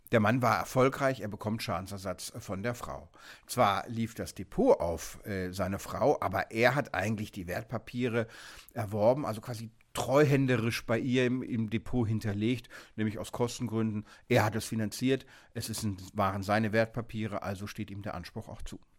O-Ton: Nach der Scheidung – wem gehören die Aktien?